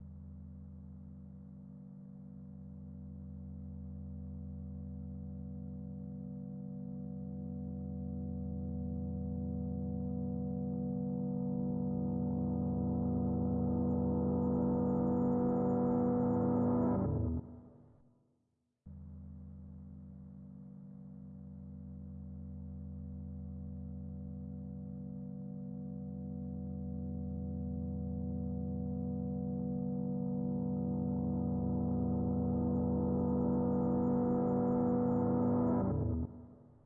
描述：模拟声音，吉他操作
Tag: 吉他 合成器